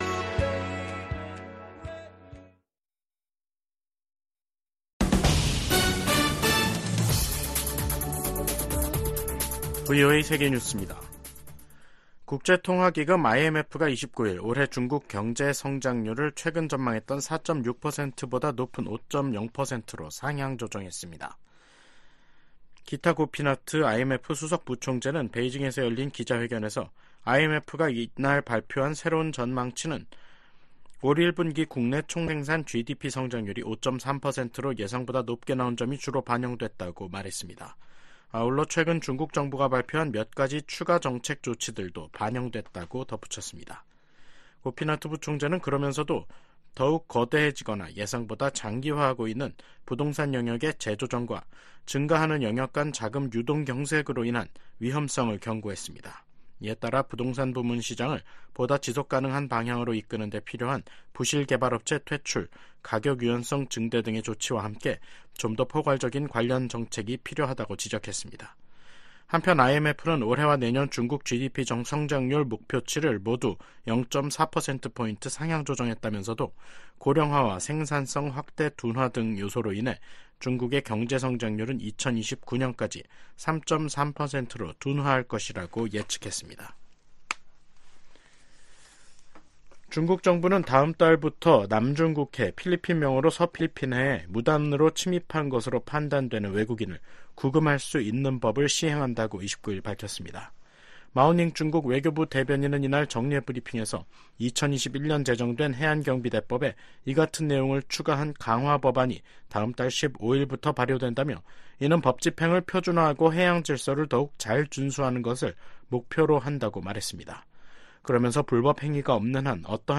VOA 한국어 간판 뉴스 프로그램 '뉴스 투데이', 2024년 5월 29일 3부 방송입니다. 미 국무부는 최근의 한일중 정상회담과 관련해 북한 문제에 대한 중국의 역할이 중요하다는 점을 거듭 강조했습니다. 전 세계 주요국과 국제기구들이 계속되는 북한의 미사일 발사는 관련 안보리 결의에 대한 명백한 위반이라고 비판했습니다.